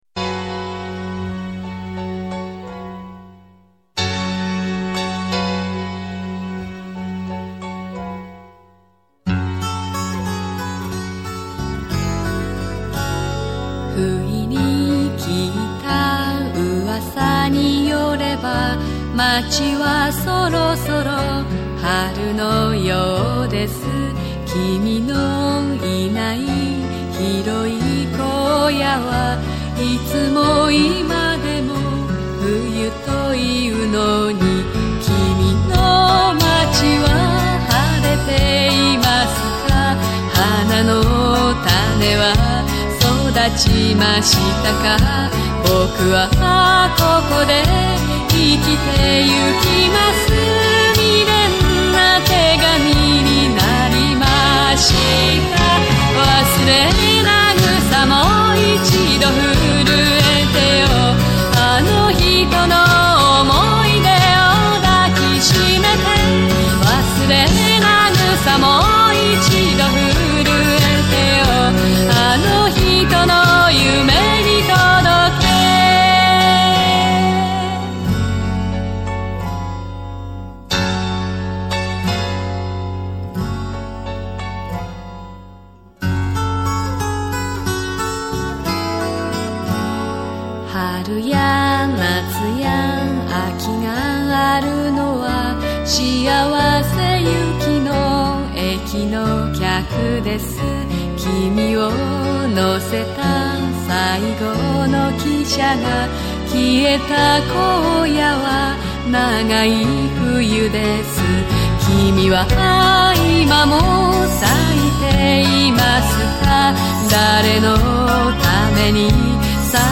駅にまつわる歌謡曲